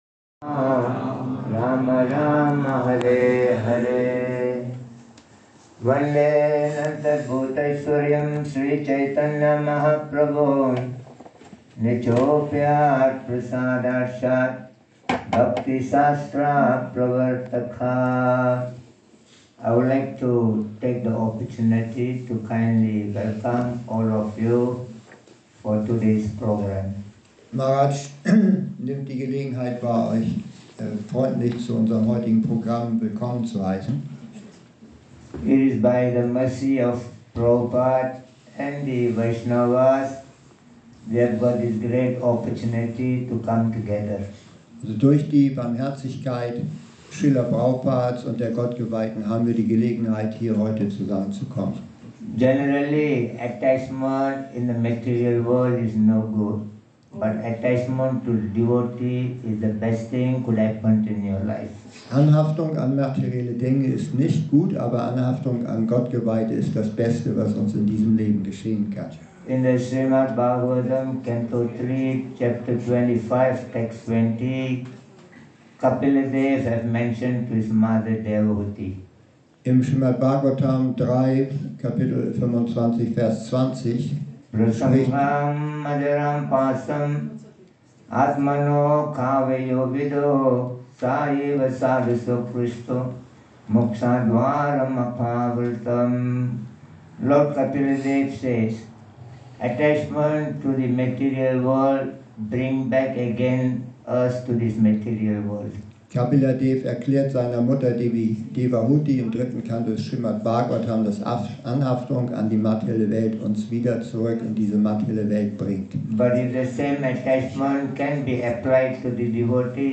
Begleite uns zu einem spirituell aufgeladenen und belebenden Vortrag